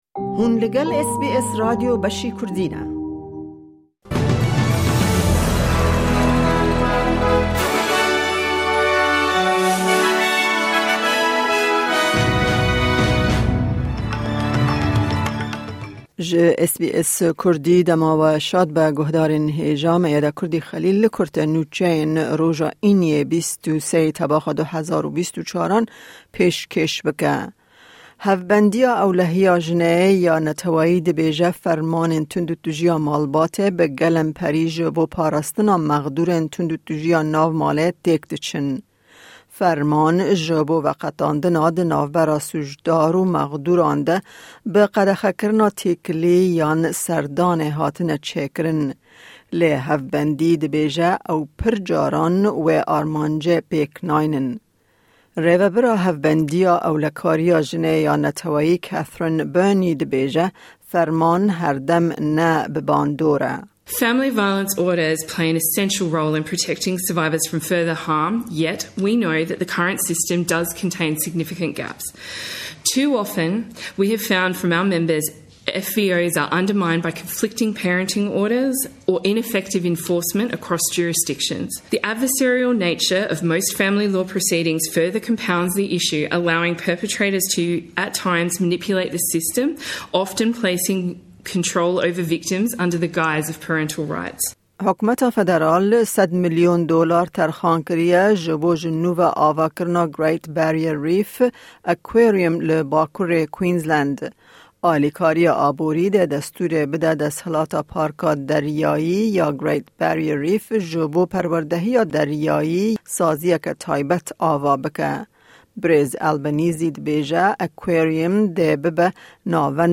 Kurte Nûçeyên roja Înî 23î Tebaxa 2024